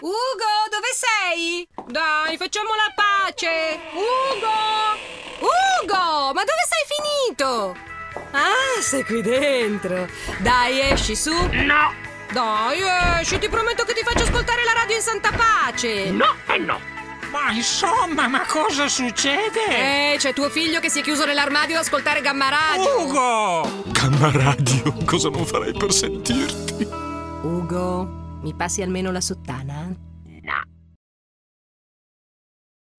VOICEOVER spot radio per GAMMA RADIO - TUMTUM Studio
Play Video Play Video Play Video Play Video Play Video Play Video Play Video Play Video Play Video Realizzazione Spots Radio per GAMMA RADIO